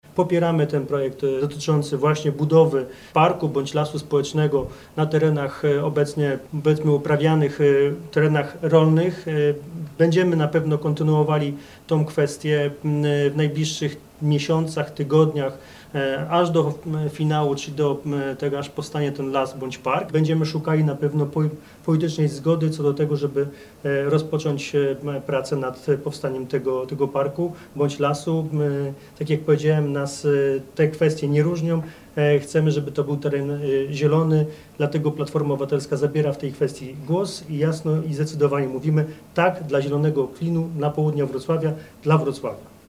Platforma Obywatelska zorganizowała konferencję w sprawie poparcia Zielonego Klina Południa Wrocławia.
Pozostaje oczywiście dyskusja czy teren ten ma być lasem społecznym czy parkiem – mówi Michał Jaros.